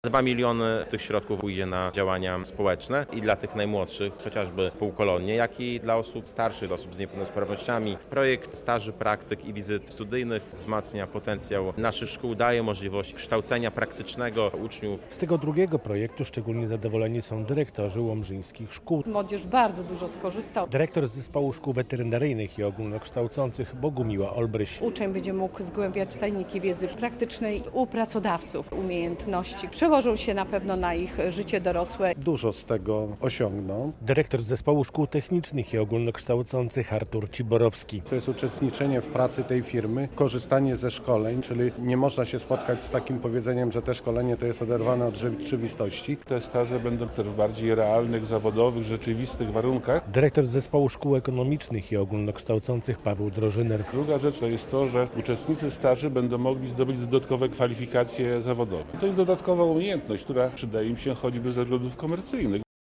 10 mln zł z EFS dla Łomży - relacja